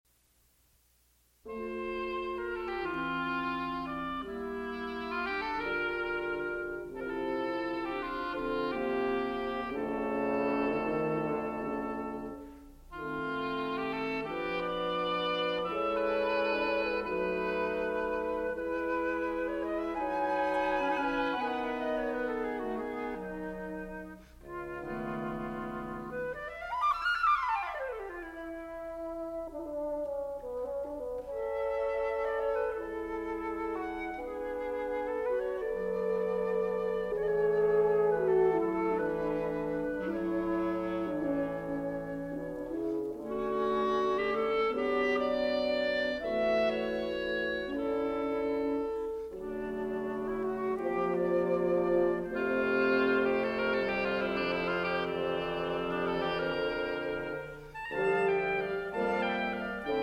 William Kincaid - Flute
Oboe
Clarinet
French Horn
Bassoon